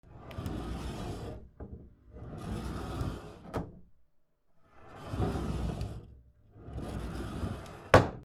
/ K｜フォーリー(開閉) / K05 ｜ドア(扉)
引き戸